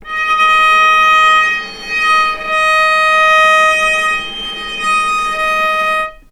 vc_sp-D#5-mf.AIF